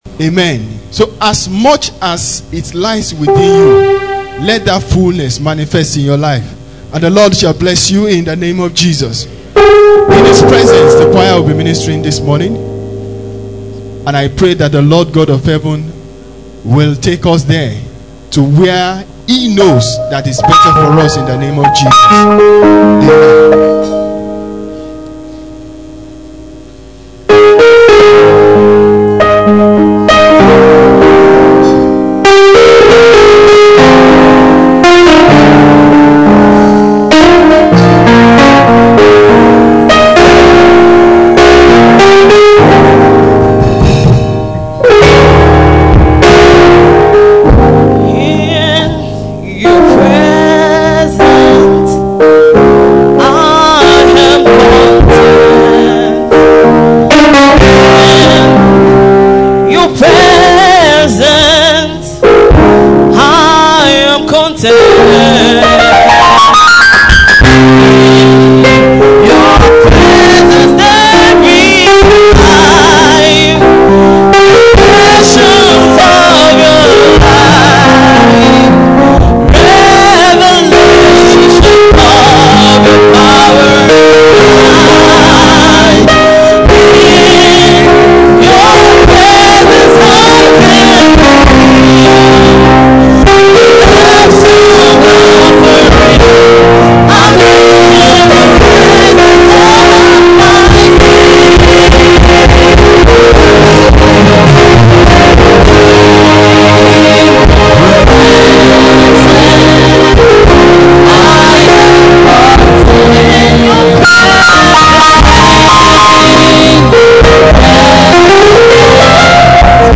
Posted in Sunday Service